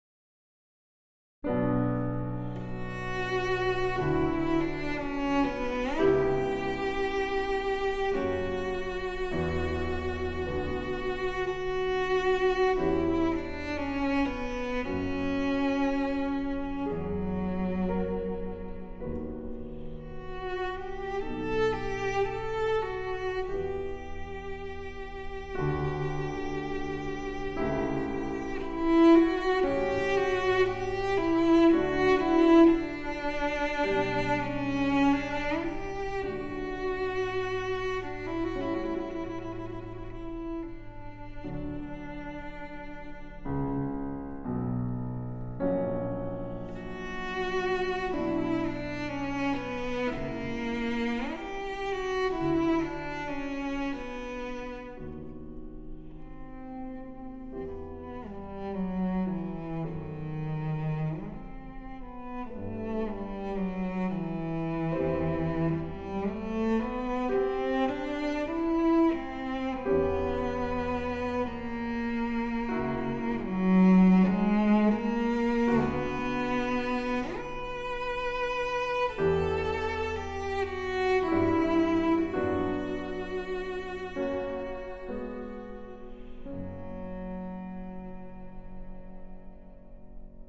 ☆才華洋溢、沈穩內斂的大師級演奏風範，不必刻意炫技卻更教人心動！
☆極簡錄音處理，再現最真實質樸且極具典雅氣質的樂器溫潤光澤。